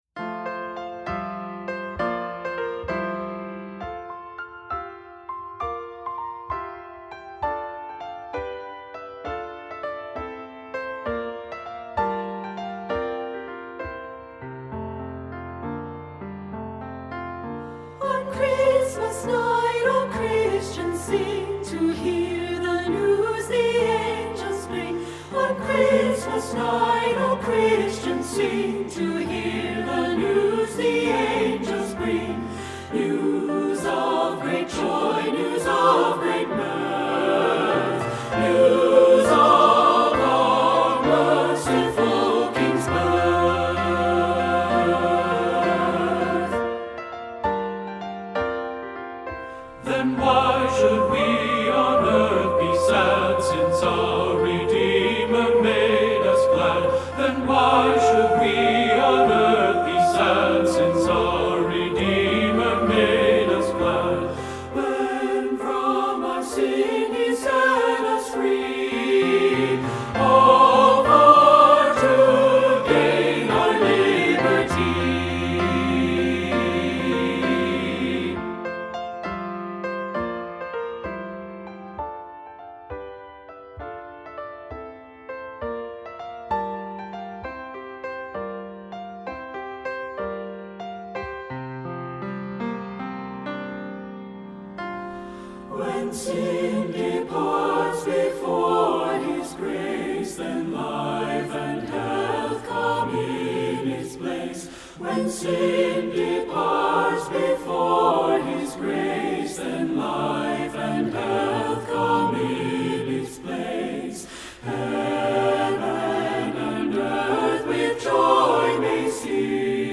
SATB with piano